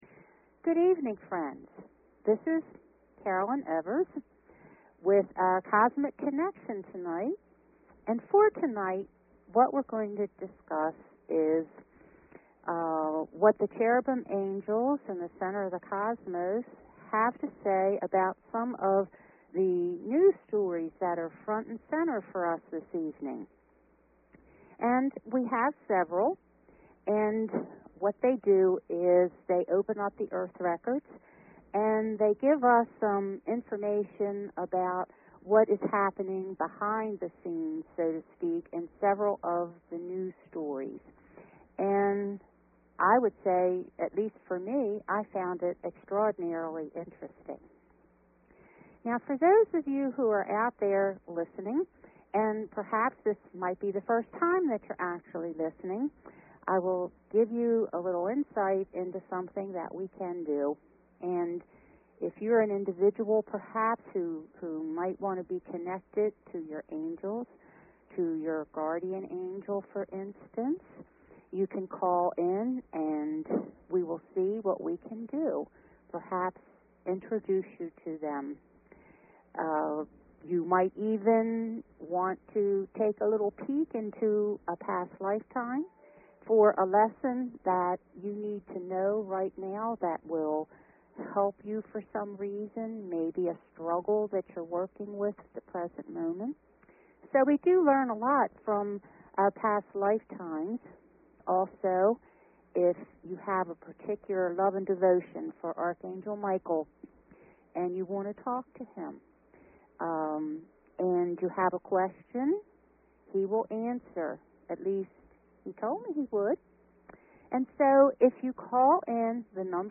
Talk Show Episode, Audio Podcast, Cosmic_Connections and Courtesy of BBS Radio on , show guests , about , categorized as